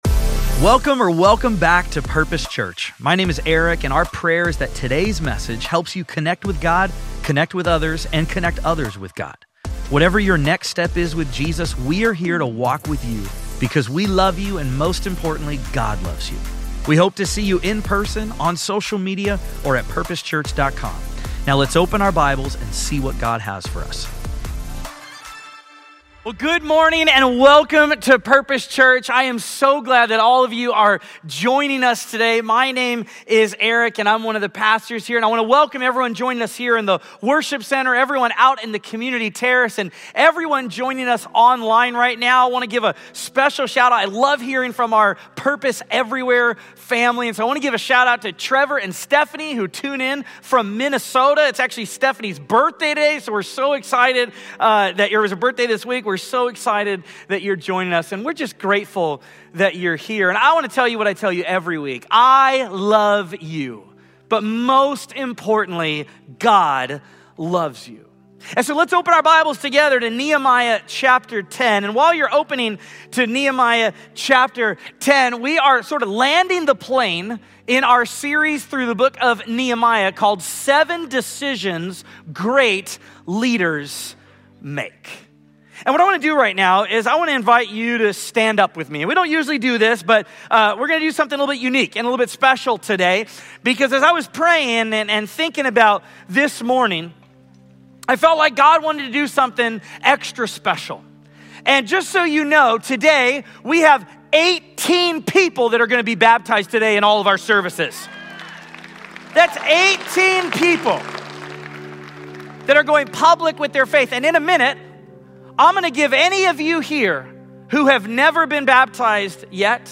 This sermon invites us to examine our own convictions and consider what it looks like to follow God wholeheartedly, even when